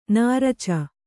♪ nāraca